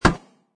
metalgrass2.mp3